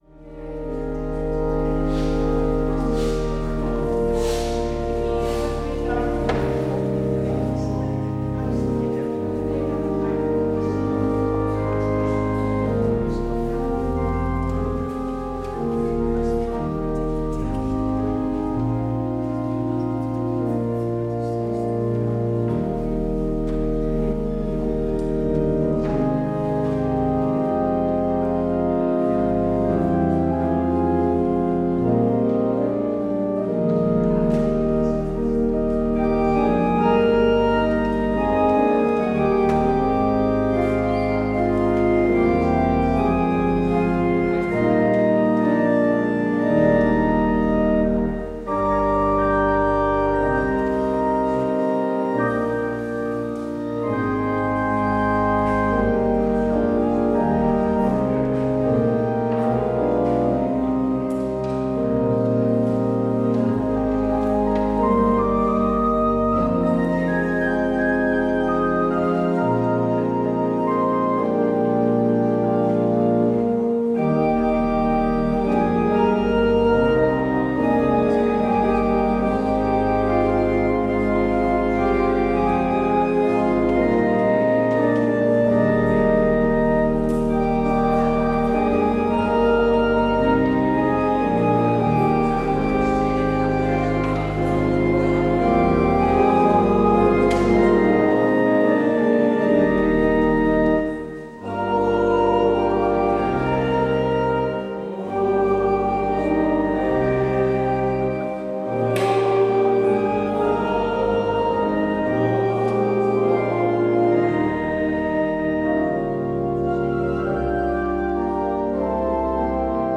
Het openingslied is: Psalm 105: 1 en 3.
Het slotlied is: NLB 908: 6 en 7.